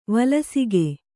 ♪ valasige